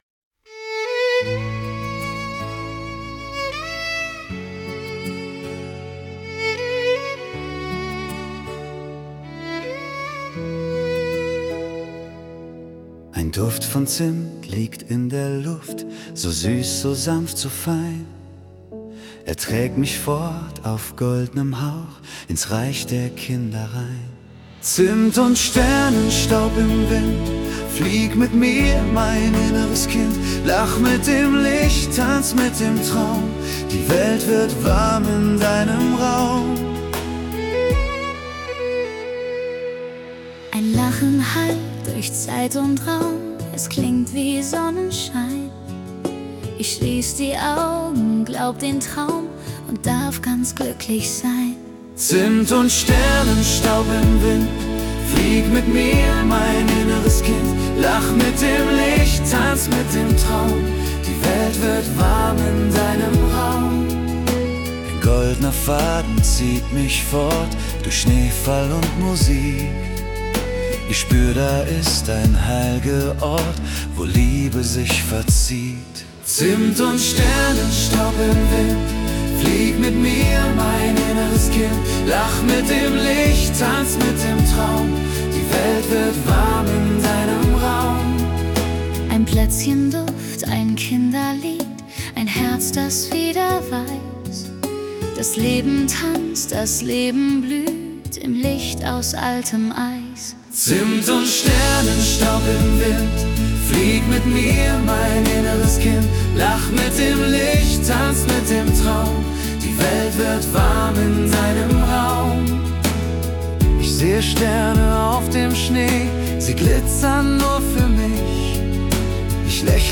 → Kindliche Freude, Duft, Lachen und Staunen.